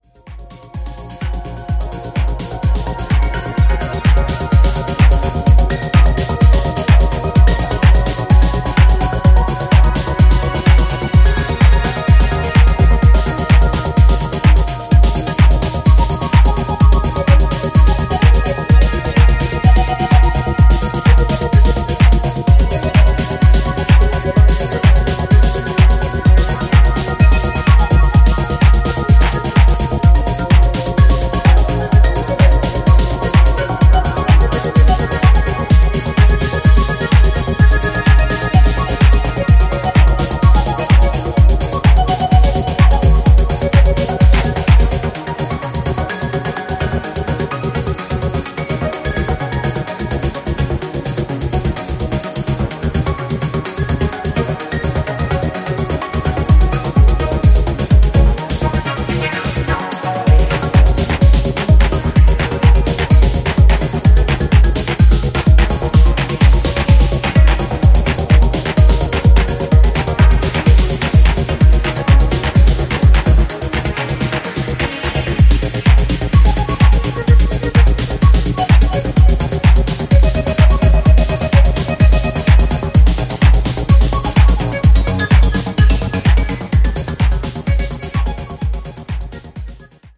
デトロイト古参ファンから新規ファンまで幅広く届いて欲しい、オールドスクール愛溢れる作品です。